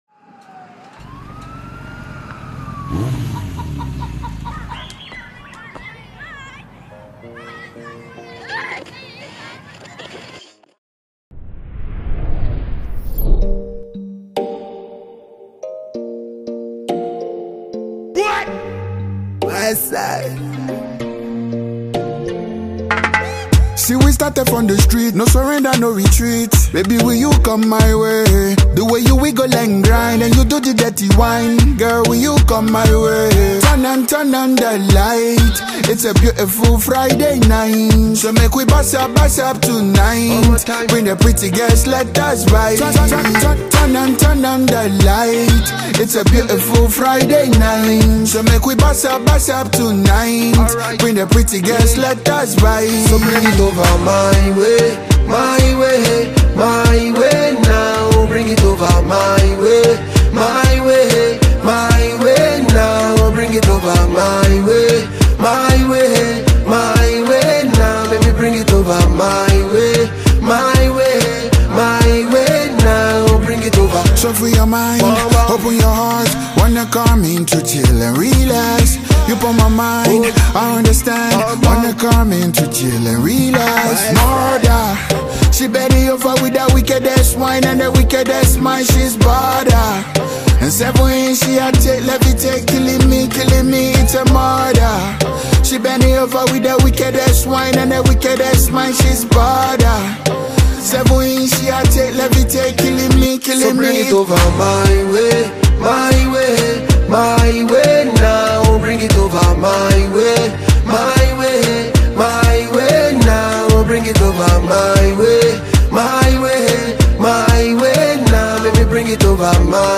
a dancehall singer